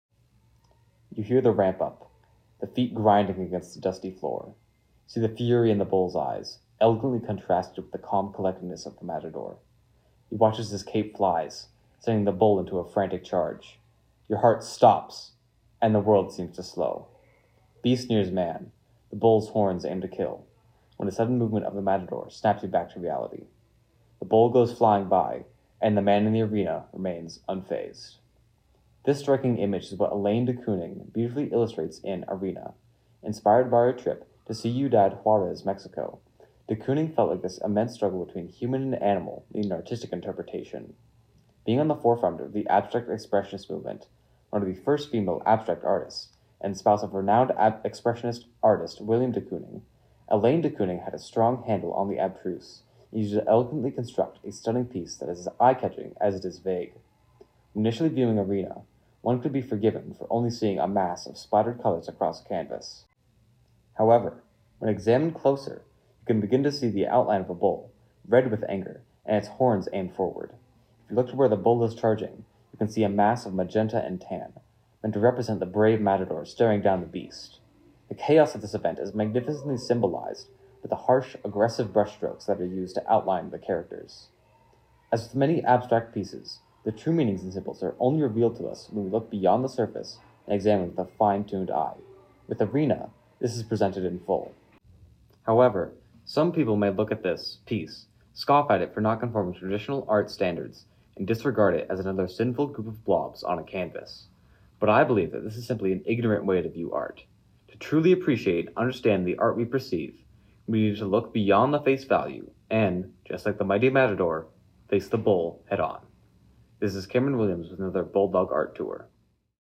Audio Tour – Bulldog Art Tour